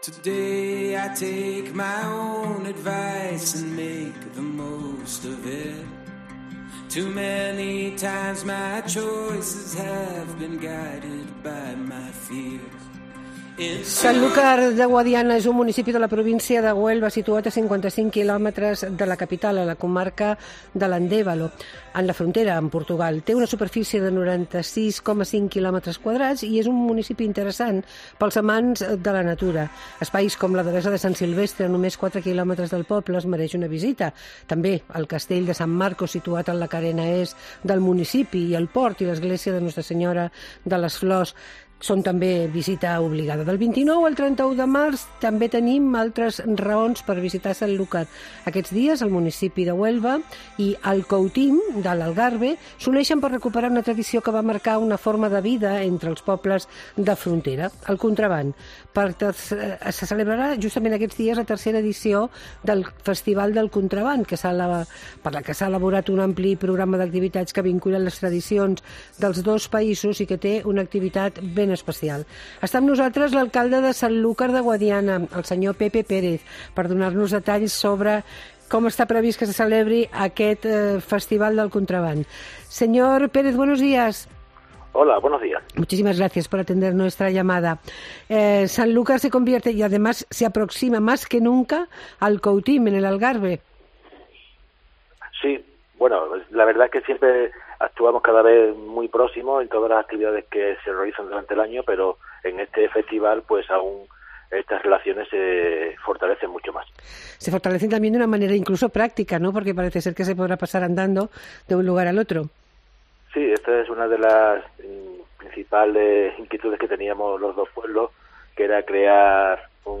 Al març, a Sancúlar de Guadiana, se celebra el Festival de Contraban. Ens ho explica l'alcalde.